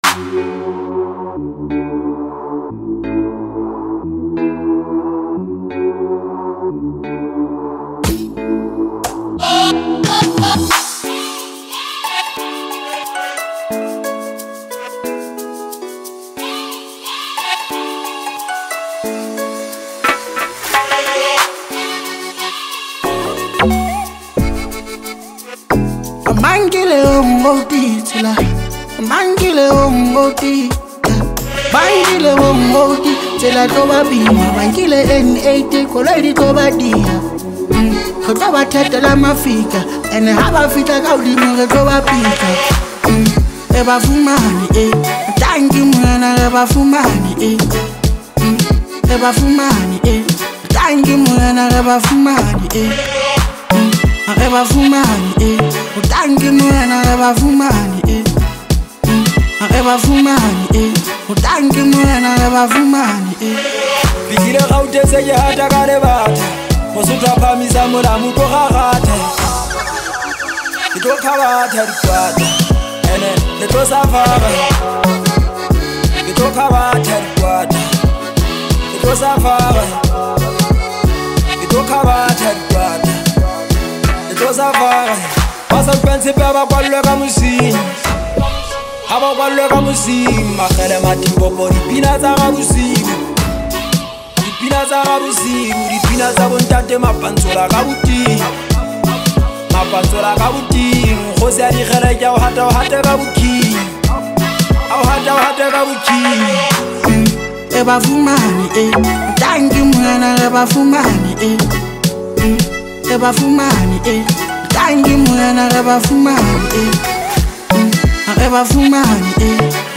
Foreign MusicSouth African
” which is a collection of seven incredible Hip Hop tracks.
It has got everything from catchy beats to heartfelt lyrics.